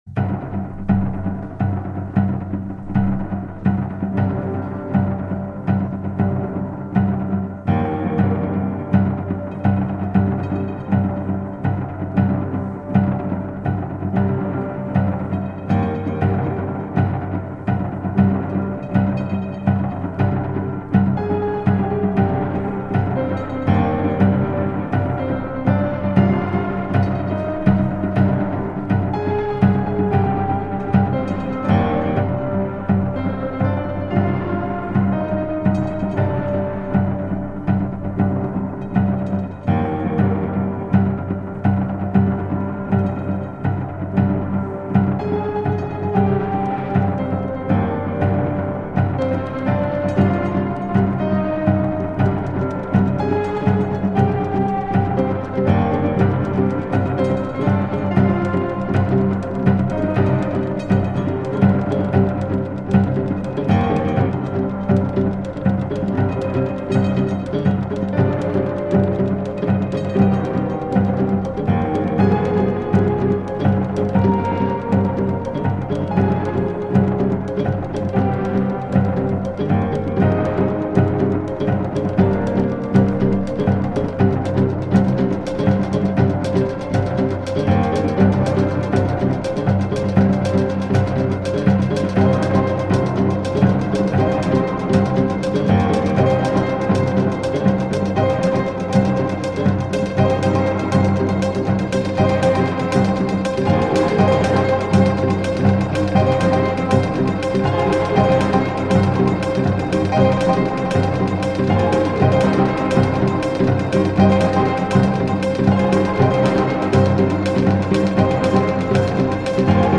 クラウトロックの流れを感じさせるミニマル・ミュージックとクラッシックの要素が交ざりあった傑作！！